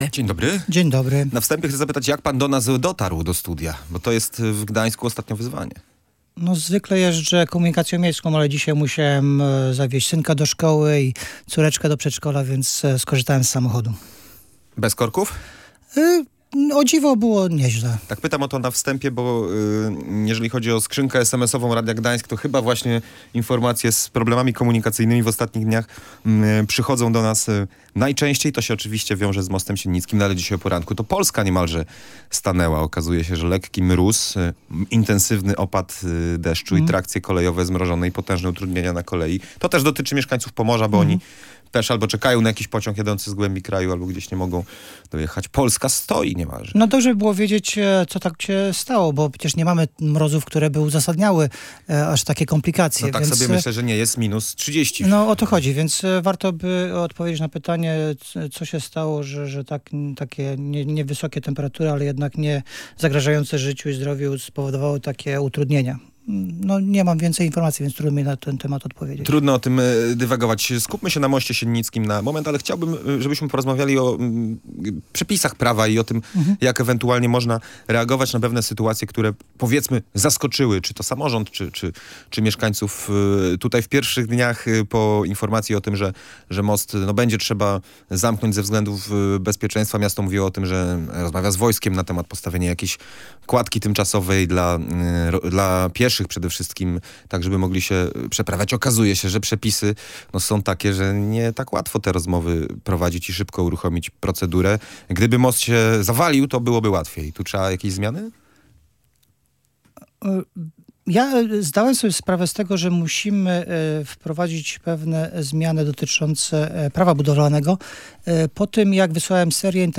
Potrzebne są zmiany dotyczące prawa budowlanego. Pokazuje to sytuacja z mostem Siennickim - mówił w Radiu Gdańsk poseł Koalicji Obywatelskiej